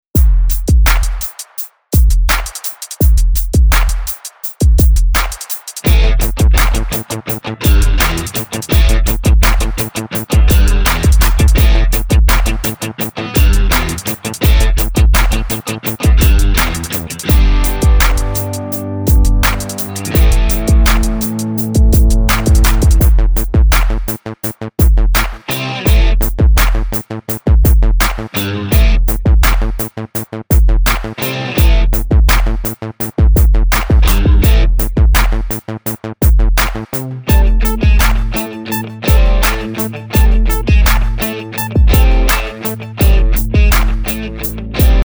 Listen to the instrumental version of this song.